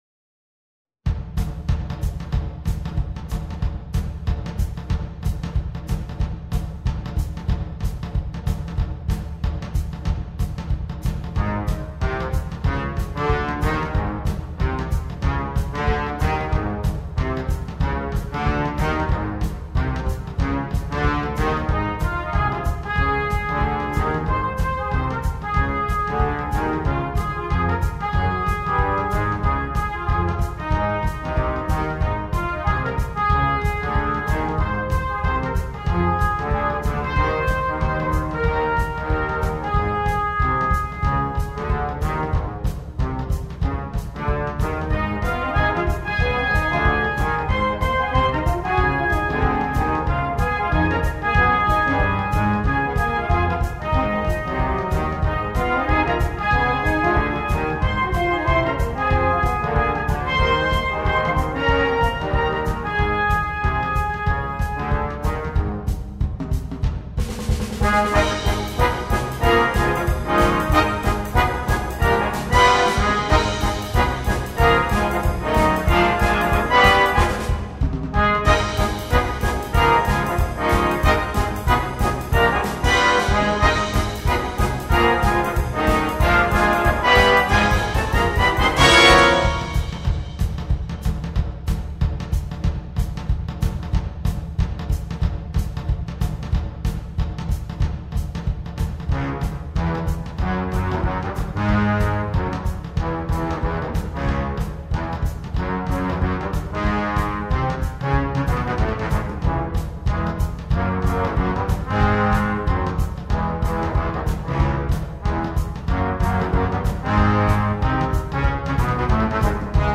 Swing Arrangement
this arrangement is a high energy swing treatment
this work features ample solo space for the drummer